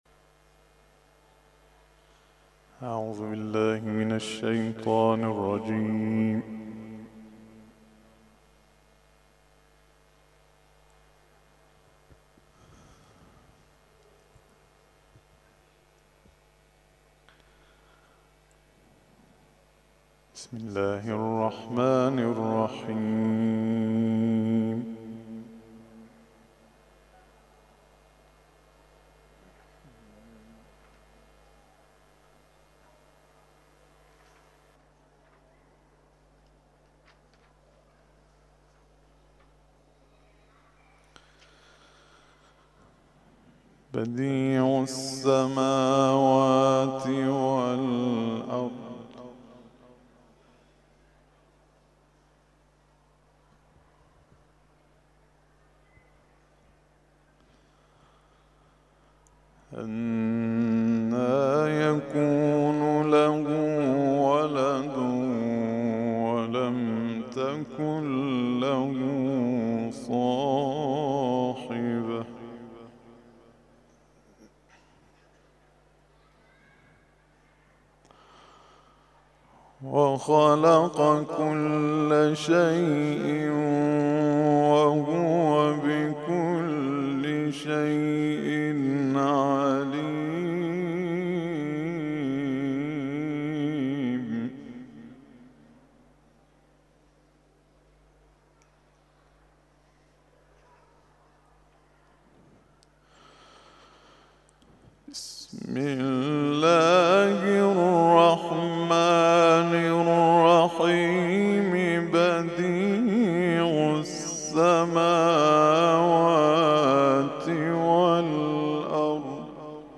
تلاوت ظهر - سوره انعام آیات (۱۰۱ الی ۱۰۷)